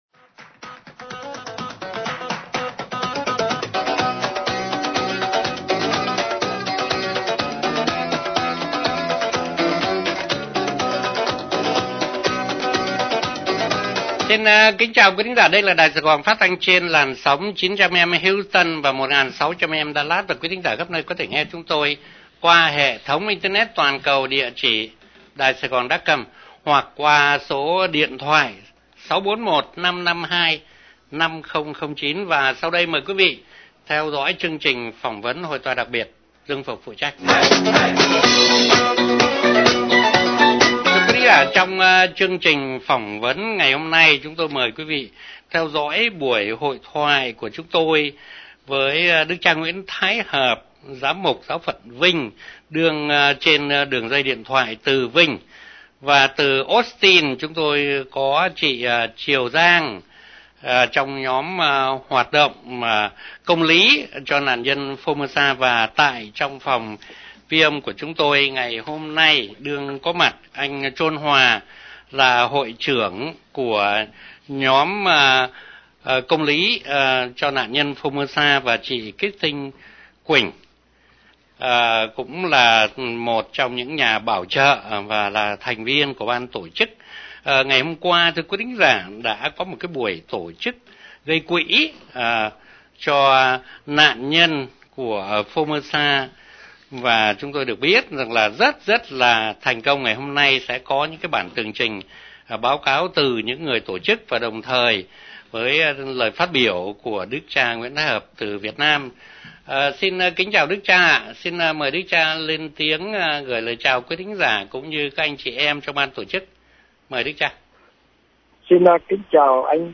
Đài Radio Saigon Houston 900 AM Phỏng Vấn
ĐGM. Phaolo Nguyễn Thái Hợp, GM. Địa phận Vinh